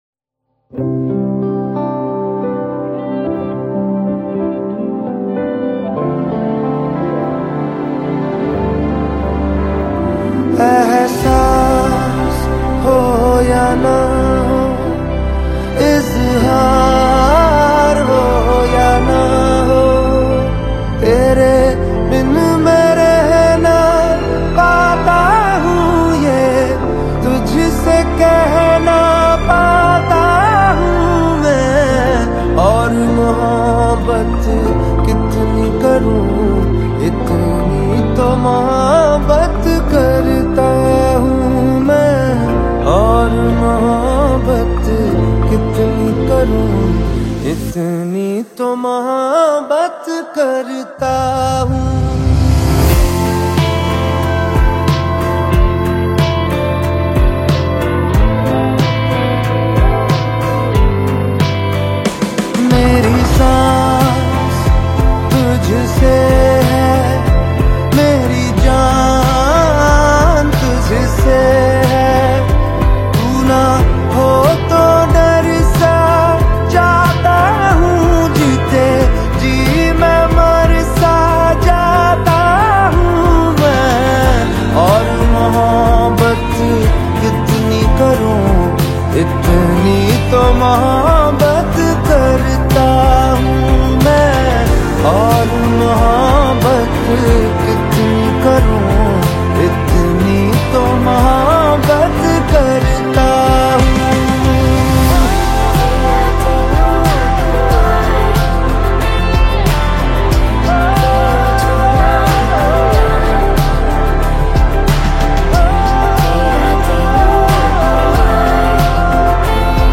deeply emotional romantic song
Bollywood Songs